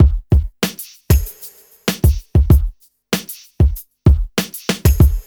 4RB96BEAT1-L.wav